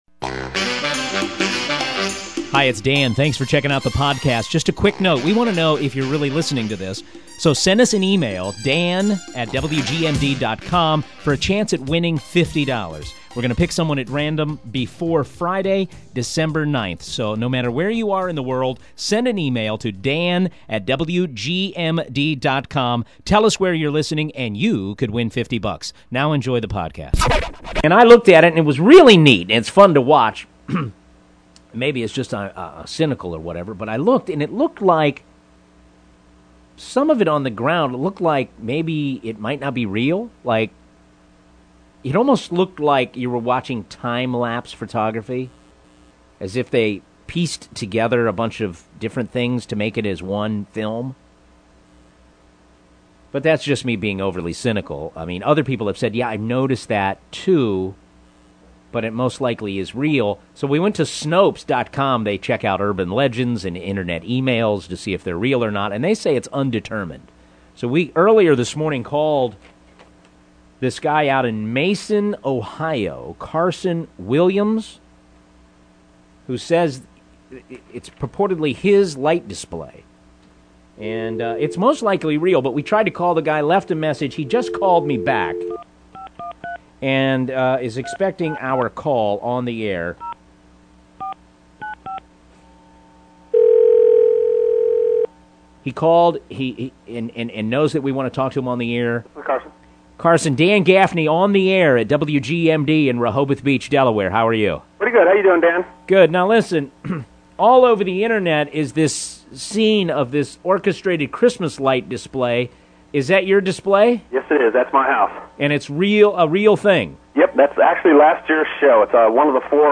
on-air interview